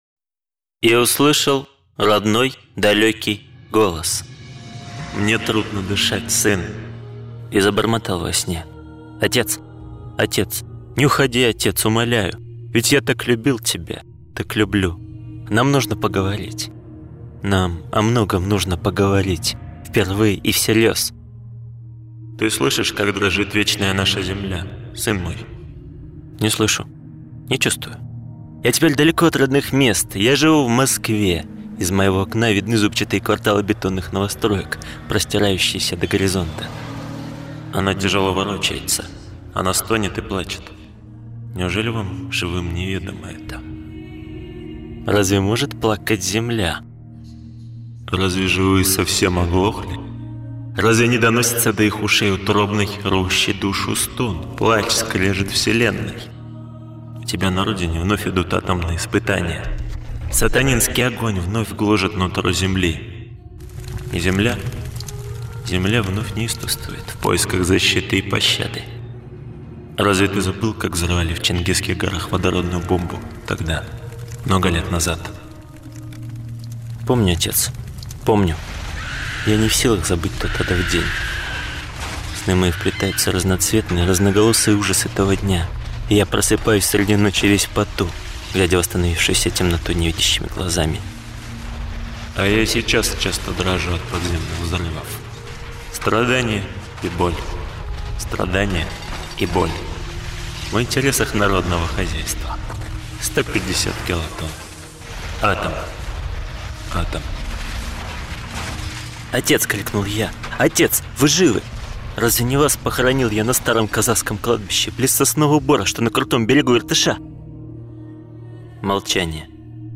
Весь сценарий звукового ряда разрабатывали сами. Записывали голоса новорожденных сестер и братьев, имитировали голоса домашних животных.